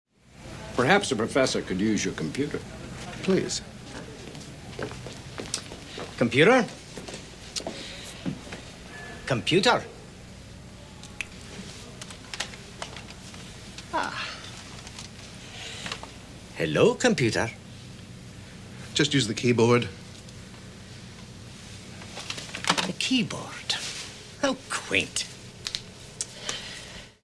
So when I hear people object to having computers that can interact with people, I often think back to Star Trek IV: The Voyage Home, which was released in 1986.
Bones suggested that Scotty could use the computer. He tried talking to it.